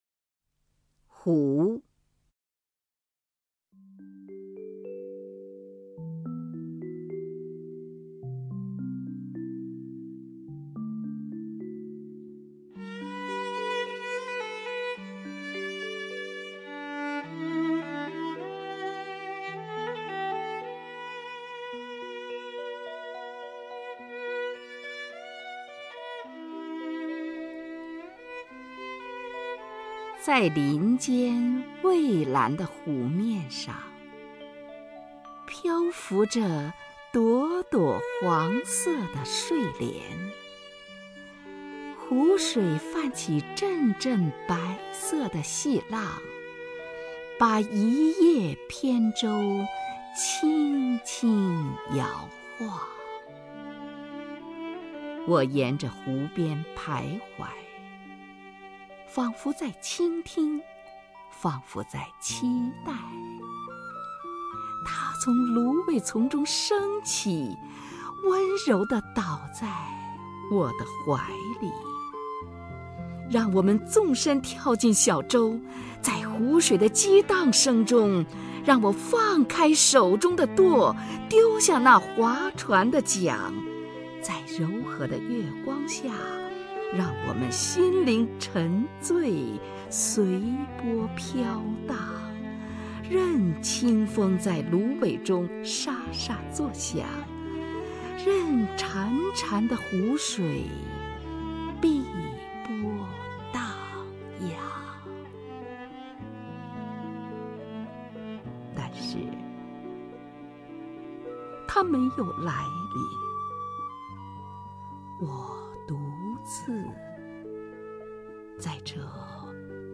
虹云朗诵：《湖》(（罗马尼亚）米哈伊·爱明内斯库)
名家朗诵欣赏 虹云 目录